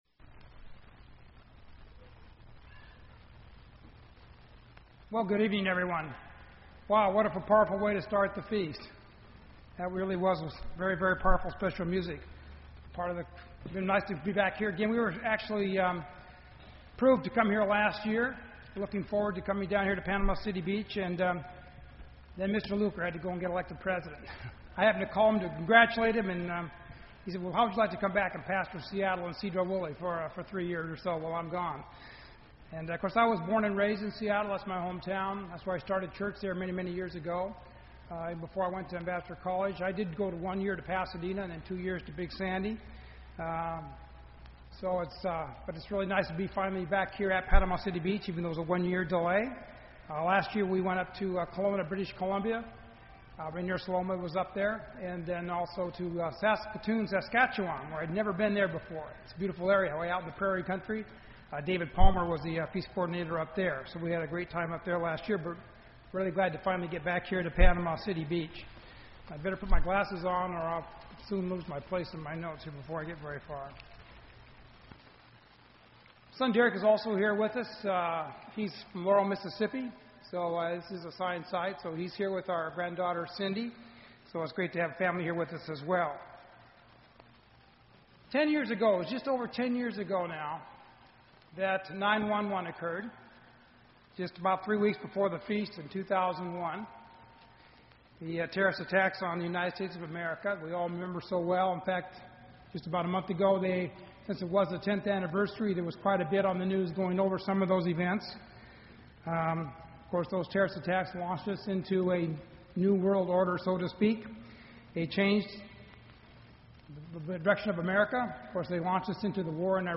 This sermon was given at the Panama City Beach, Florida 2011 Feast site.